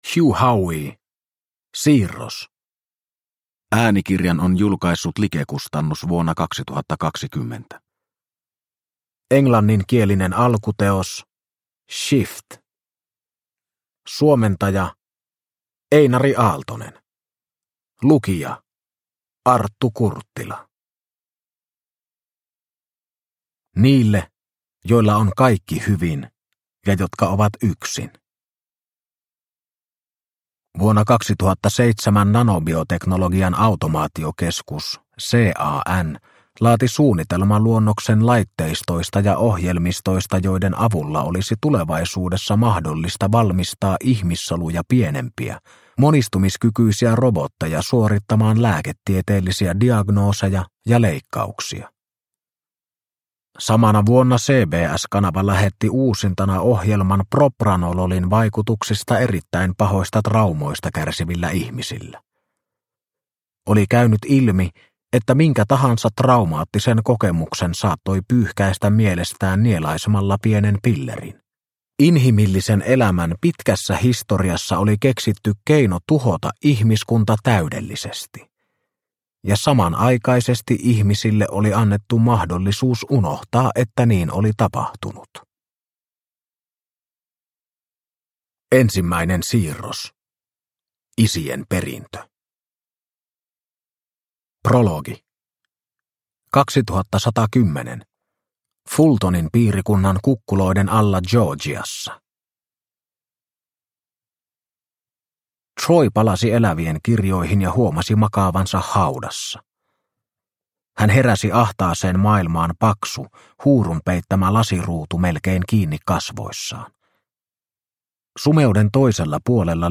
Siirros – Ljudbok – Laddas ner